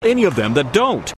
For example, them occurs twice in the American news report above, and both times it is /ðɛm/, not /ðəm/:
any_of_them_strong.mp3